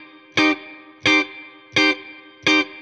DD_StratChop_85-Cmin.wav